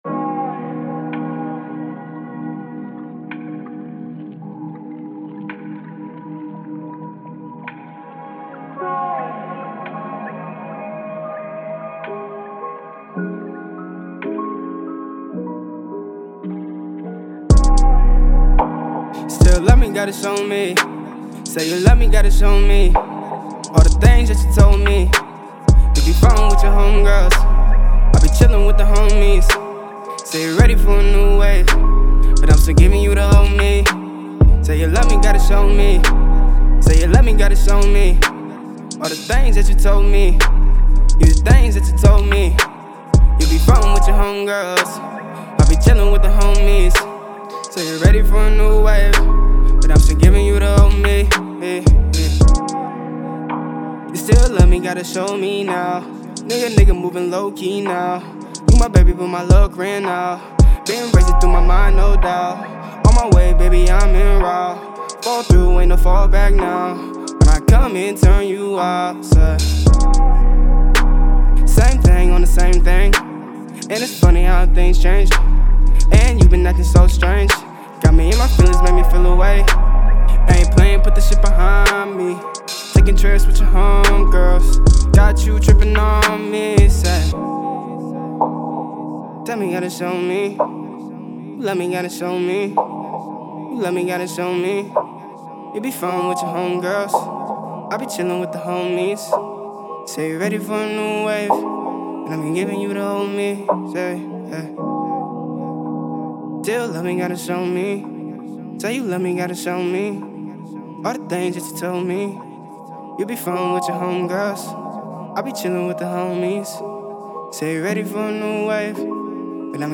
Description : R&B/Hip Hop